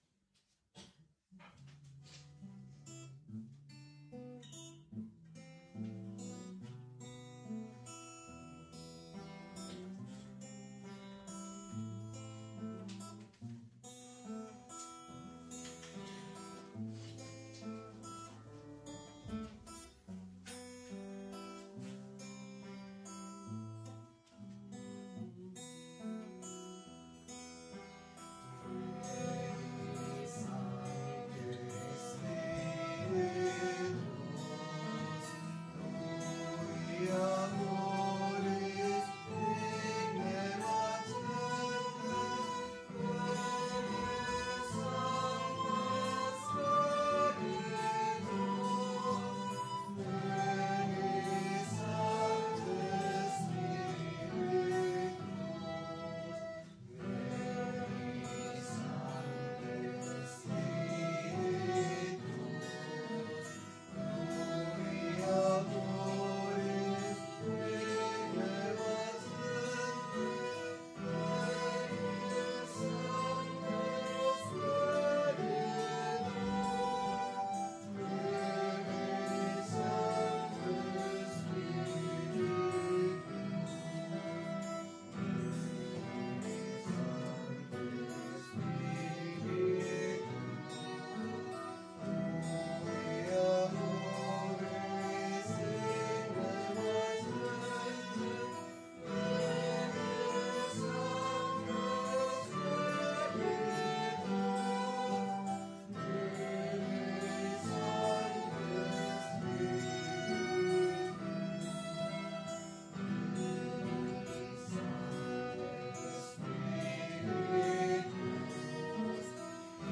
Pregària de Taizé a Mataró... des de febrer de 2001
Parròquia de la Mare de Déu de Montserrat - Diumenge 25 de febrer de 2018